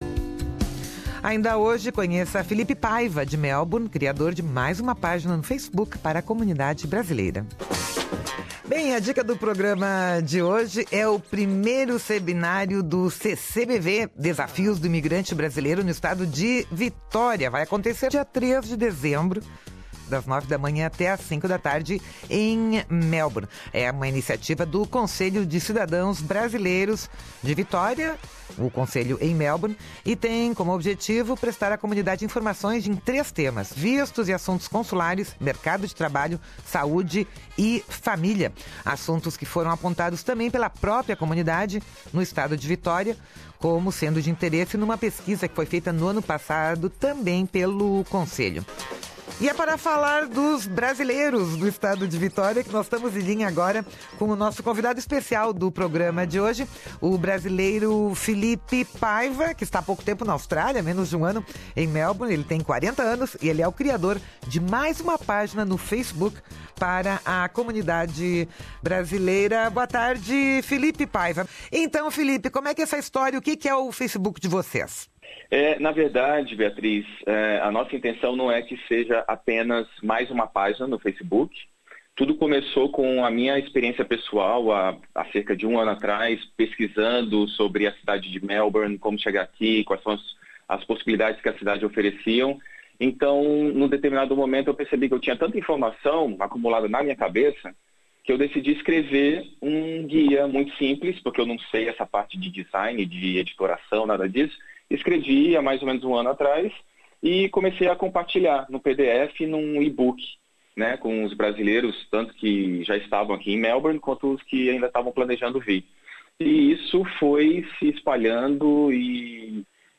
entrevista ao Programa Português da Rádio SBS